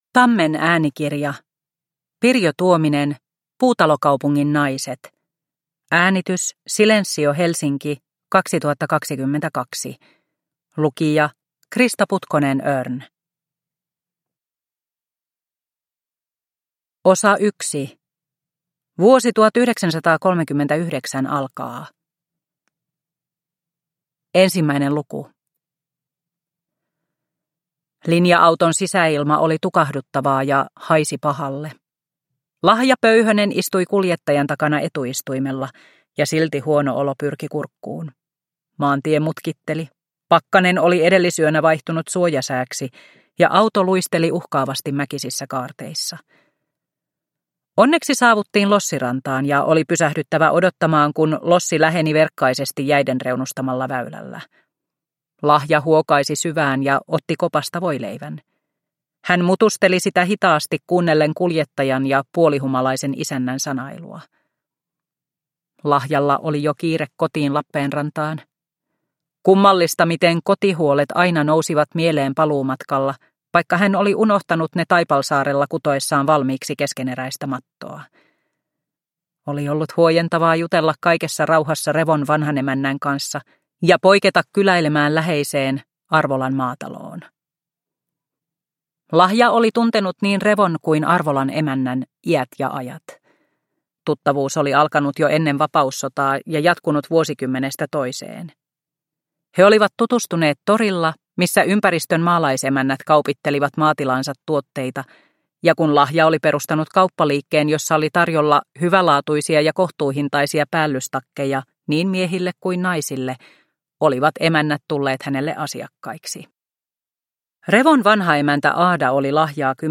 Puutalokaupungin naiset (ljudbok) av Pirjo Tuominen